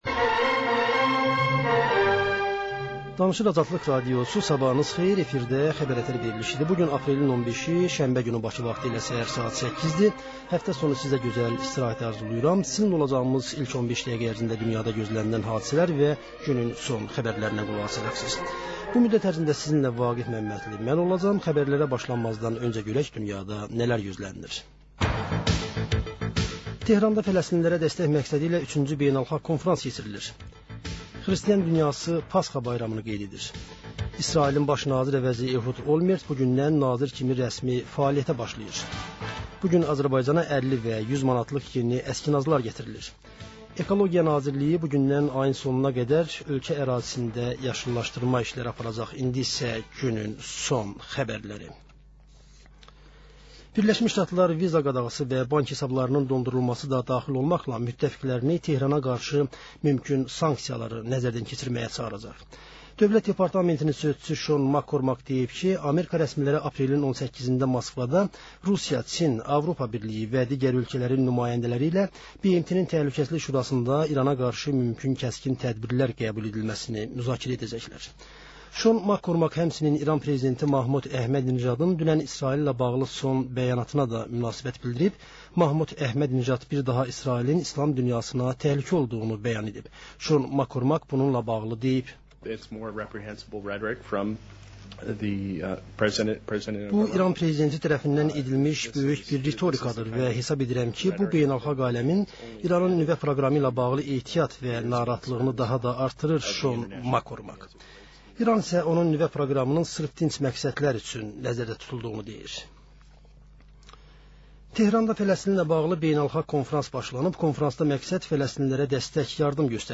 Səhər-səhər, Xəbər-ətər: xəbərlər, reportajlar, müsahibələrVə: Canlı efirdə dəyirmi masa söhbəti.